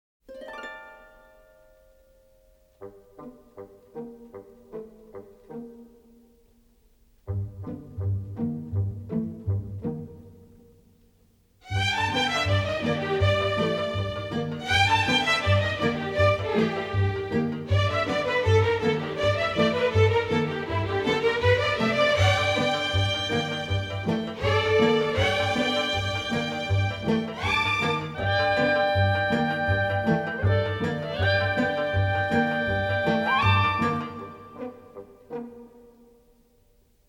tuneful Americana